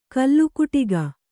♪ kallukuṭiga